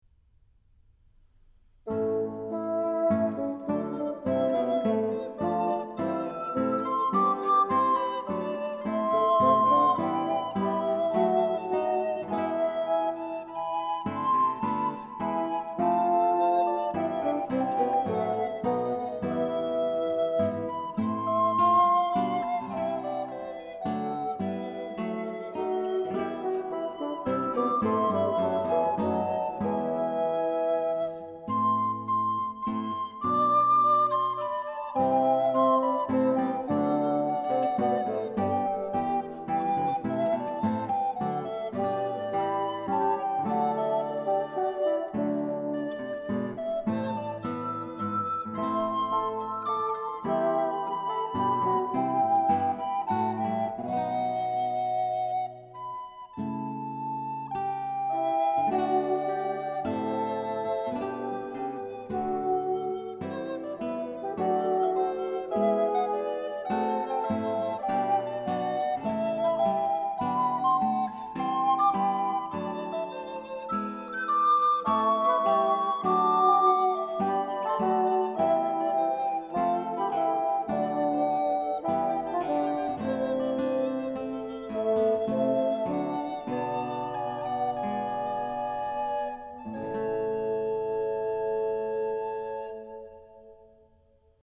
Chormusik zum Genfer Psalter, Berliner Domkantorei